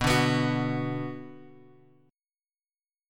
Bm chord {x 2 0 x 0 2} chord
B-Minor-B-x,2,0,x,0,2.m4a